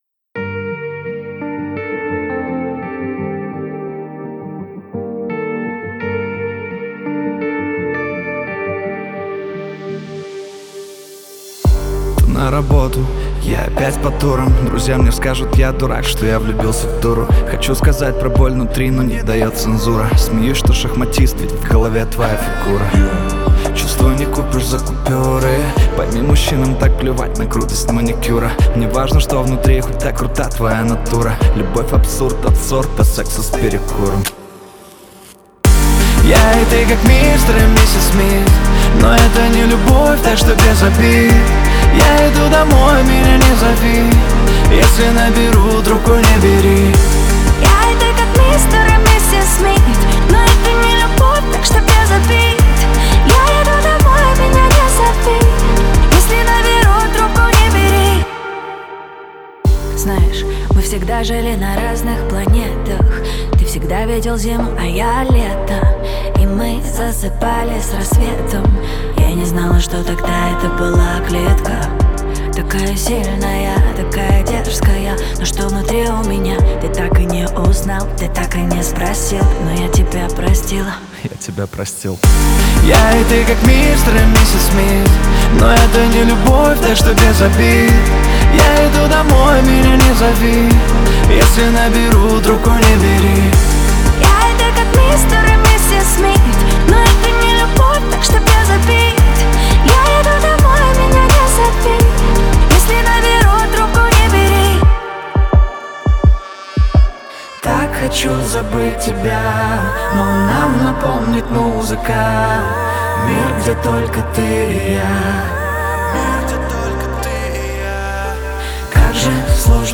это яркий поп-трек, наполненный энергией и романтикой.